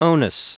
Prononciation du mot onus en anglais (fichier audio)
Prononciation du mot : onus